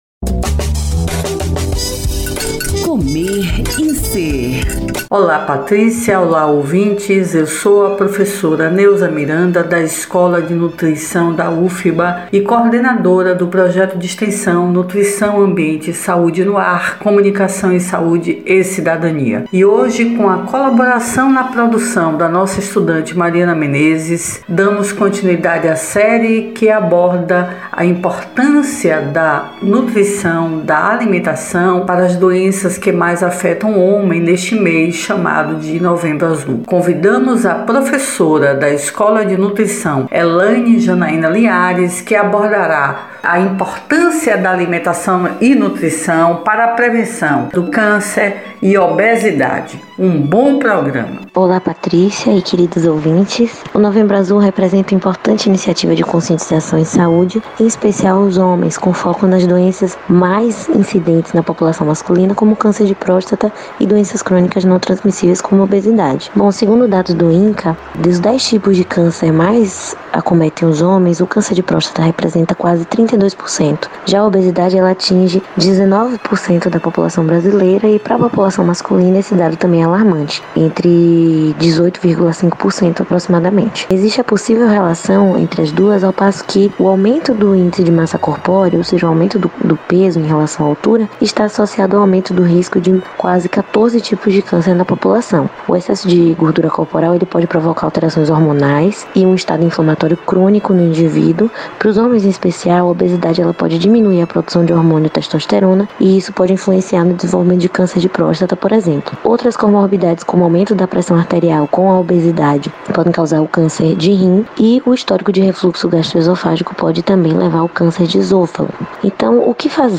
O assunto foi tema do quadro “Comer e Ser”, veiculado às Segundas-feiras pelo programa Saúde no ar, com transmissão ao vivo pelas Rádios Excelsior AM 840  e  Web Saúde no ar.